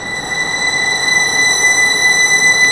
Index of /90_sSampleCDs/Roland LCDP13 String Sections/STR_Violins FX/STR_Vls Sordino